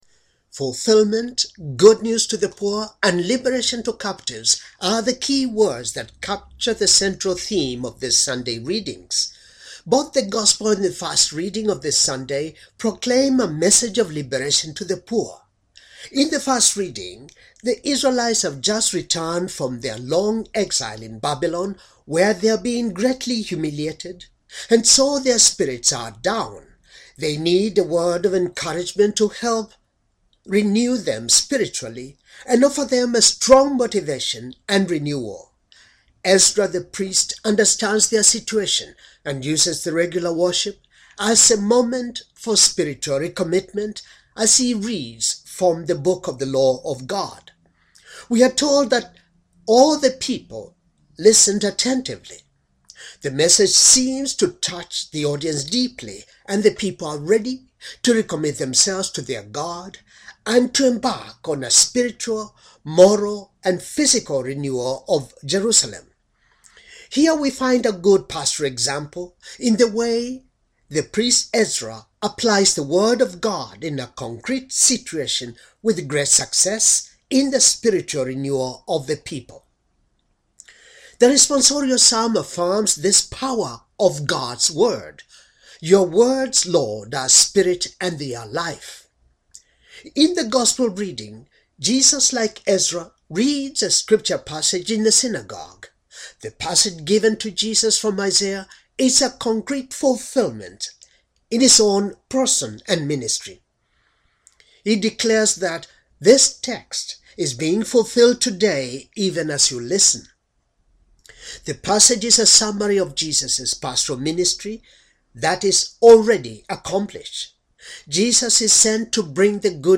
Homily for third Sunday in ordinary time, year c, fulfillment, good news, liberation, renewal